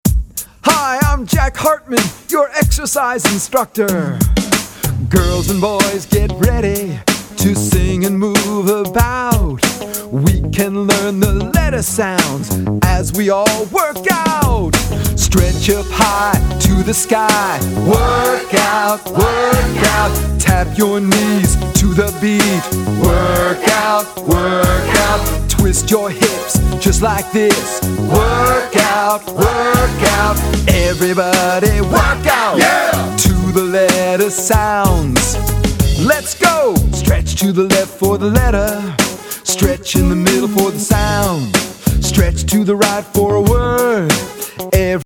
Hip-Hop and Pop Beats to Help Your Children Read